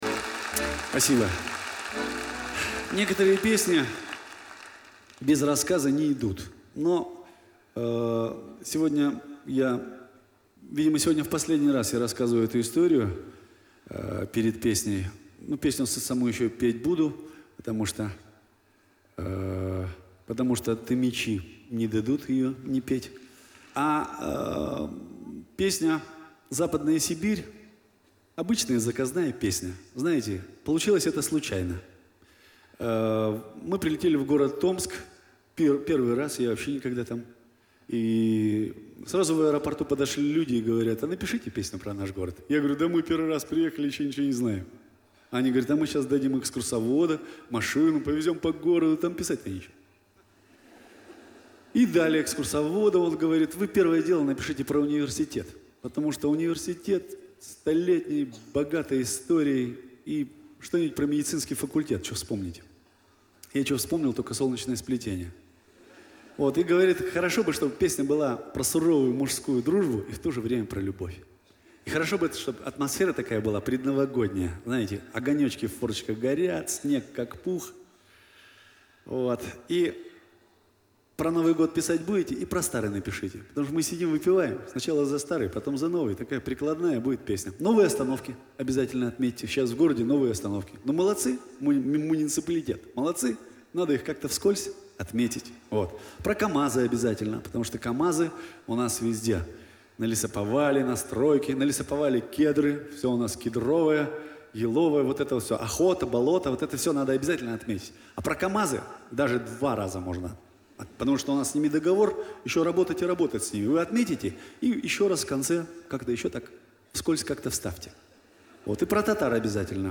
Но слова, на концерте в Кремле, были те же